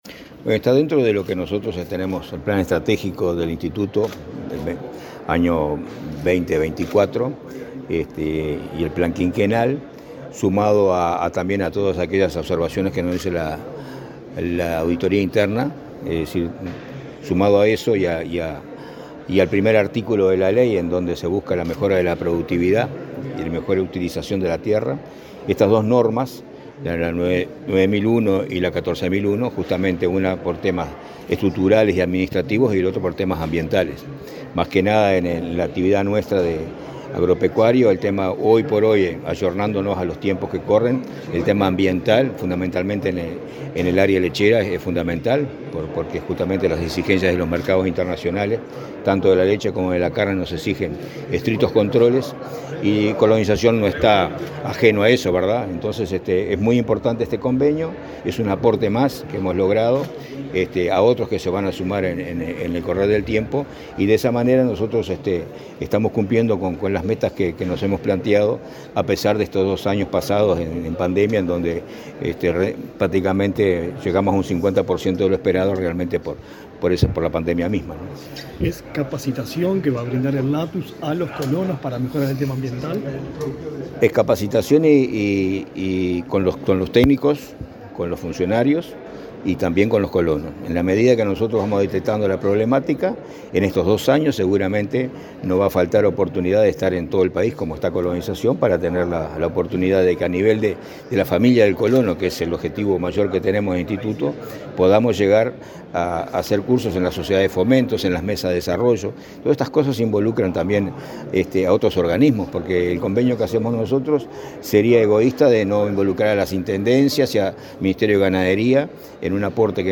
Entrevista al presidente del INC, Julio Cardozo